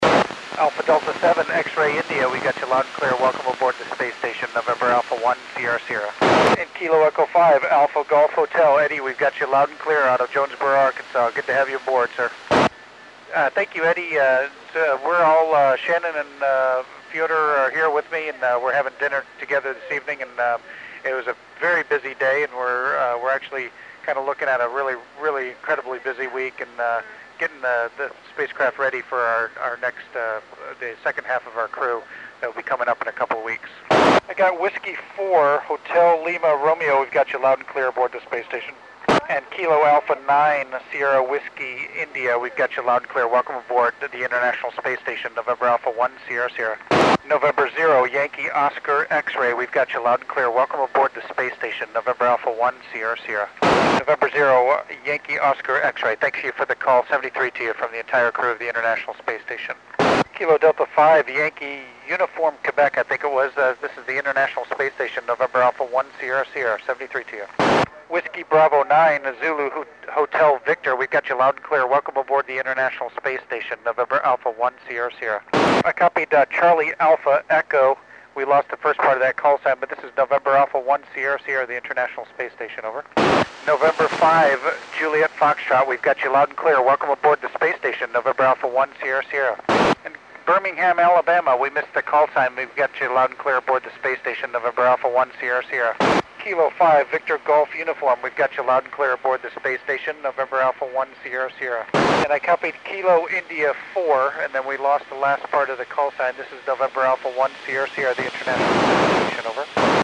Col. Doug Wheelock (NA1SS) wks U.S. stations